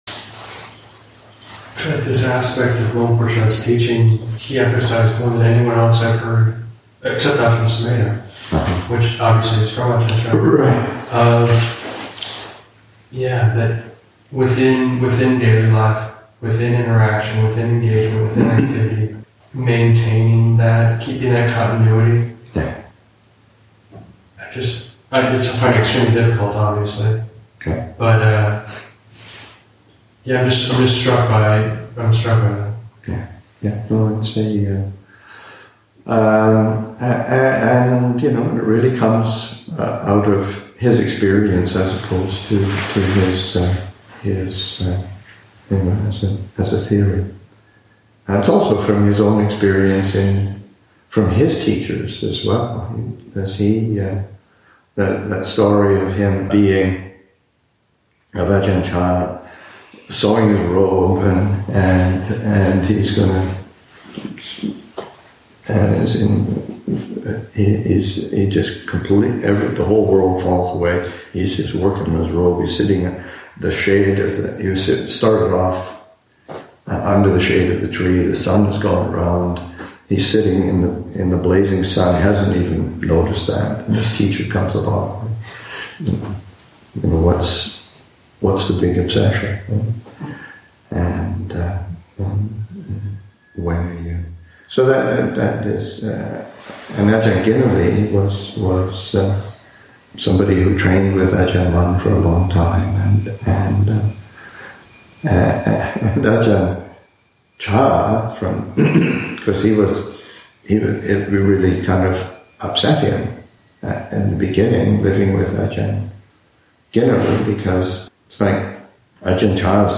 Discussion about keeping the continuity of practice within engagement and daily life.